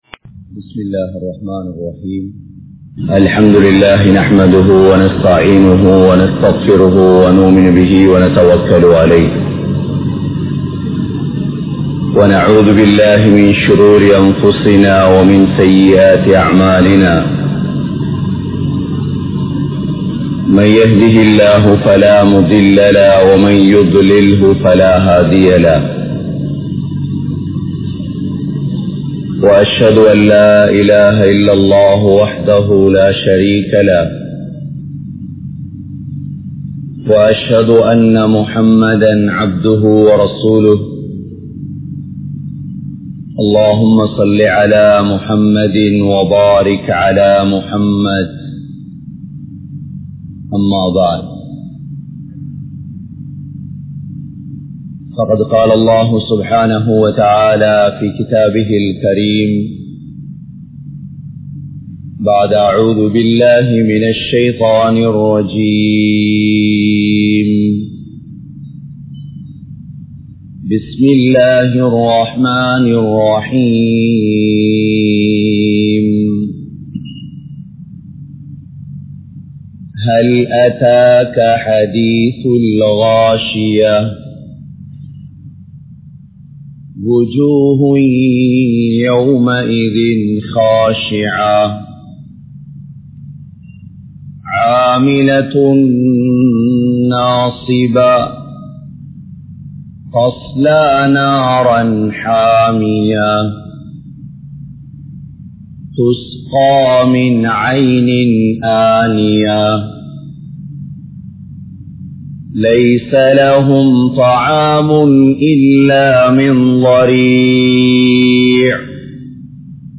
Surathul Gashiya | Audio Bayans | All Ceylon Muslim Youth Community | Addalaichenai
Colombo 03, Kollupitty Jumua Masjith